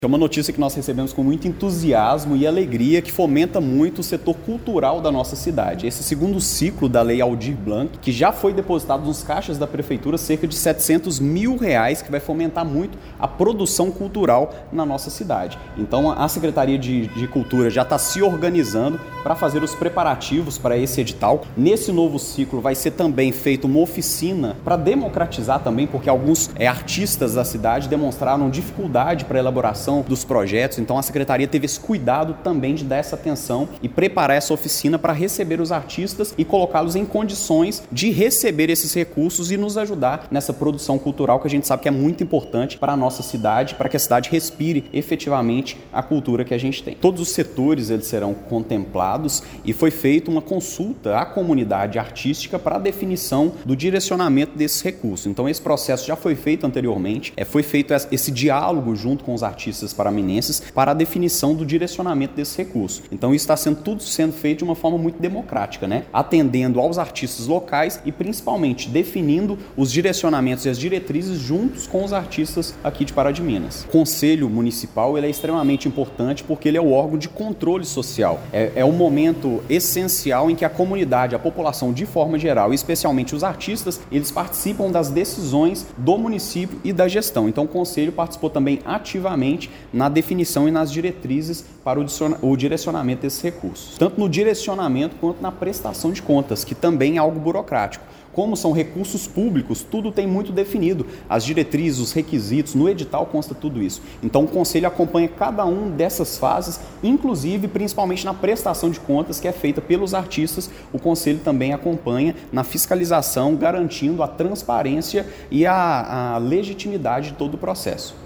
O vice-prefeito Luiz Lima ressaltou que a aplicação dos recursos da PNAB foi pensada de forma participativa, com envolvimento direto de artistas, agentes culturais e do Conselho Municipal de Políticas Culturais.